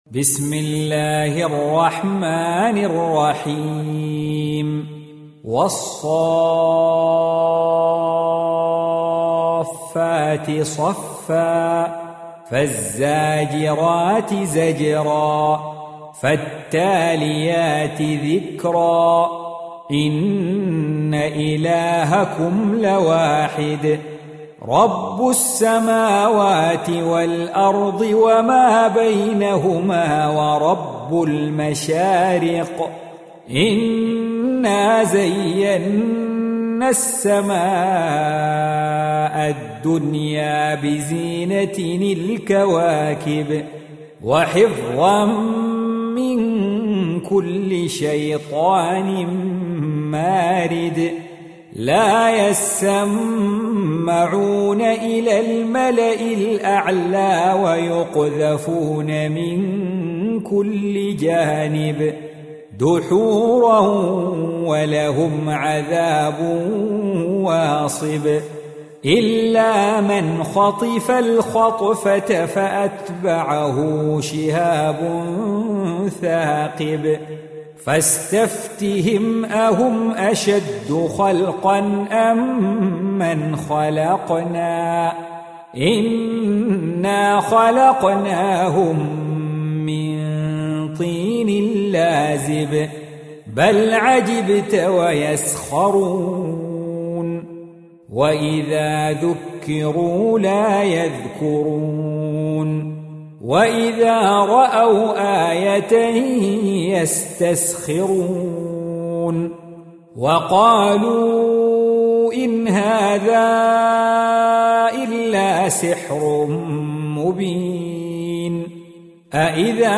Audio Quran Tarteel Recitation
Surah Repeating تكرار السورة Download Surah حمّل السورة Reciting Murattalah Audio for 37. Surah As-S�ff�t سورة الصافات N.B *Surah Includes Al-Basmalah Reciters Sequents تتابع التلاوات Reciters Repeats تكرار التلاوات